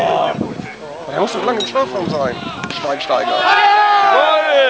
Bei den aufgenommenen Torsequenzen fallen im Hintergrund immer wieder irgendwelche komischen Kommentare, man hört Jubelschreie oder wilde Diskussionen...